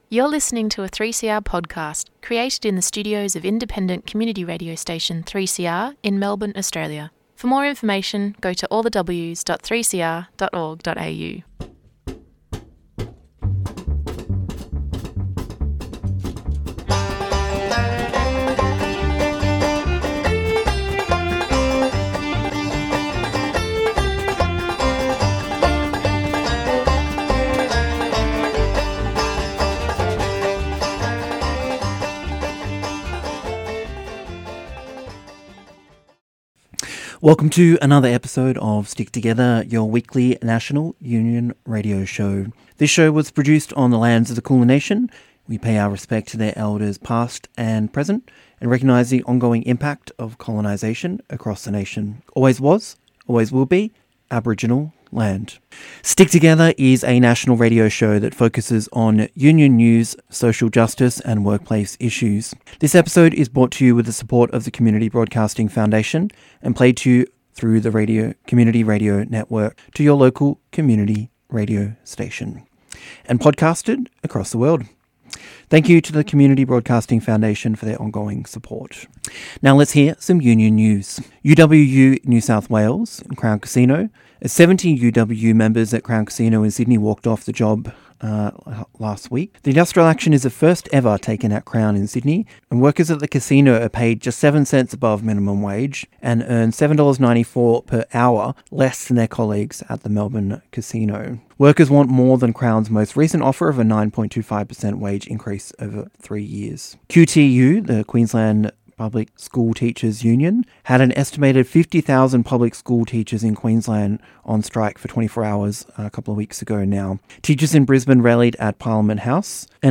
Australia's only national radio show focusing on industrial, social and workplace issues. Distributed nationally on the Community Radio Network.